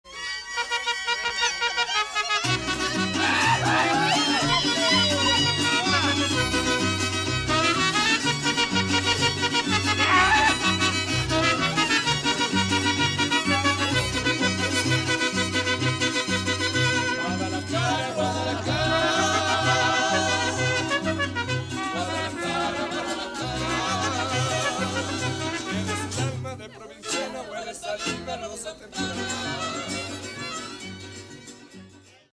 Mexican Mariachi
This is one of the best of the traditional sones and goes back probably two centuries.